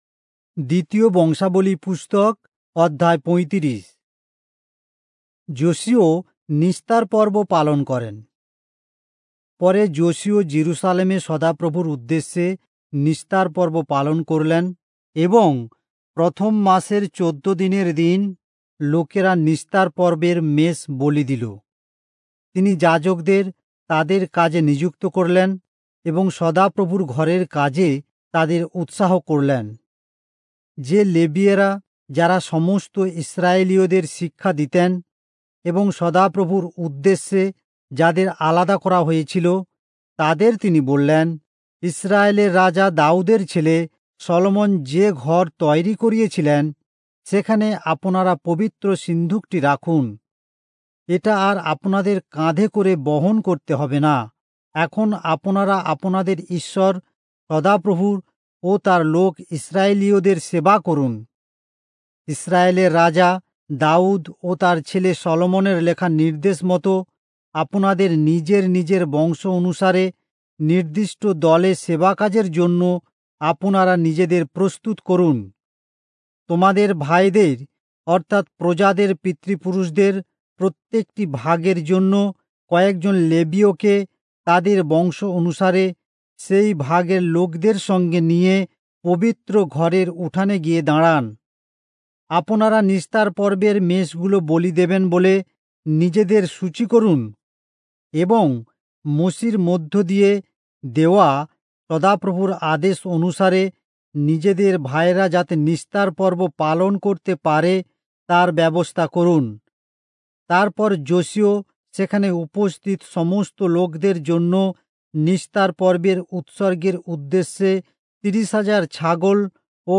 Bengali Audio Bible - 2-Chronicles 13 in Irvbn bible version